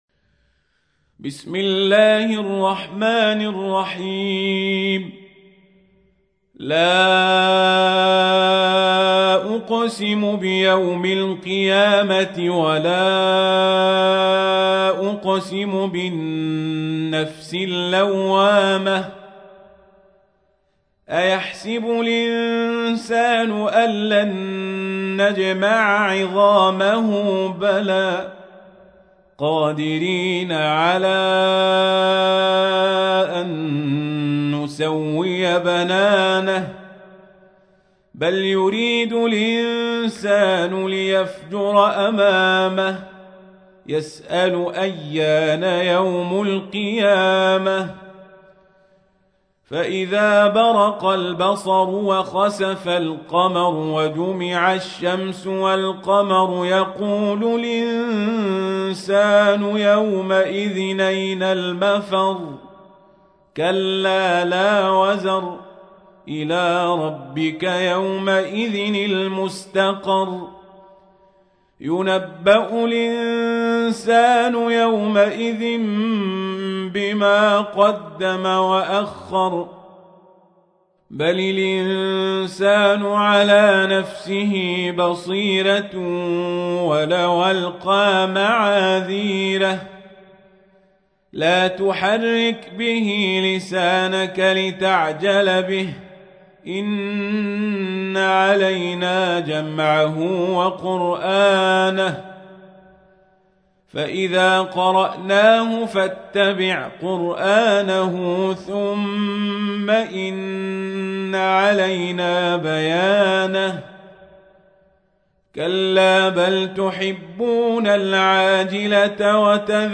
تحميل : 75. سورة القيامة / القارئ القزابري / القرآن الكريم / موقع يا حسين